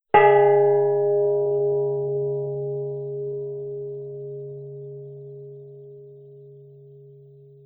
bell_large_gong_like_single.wav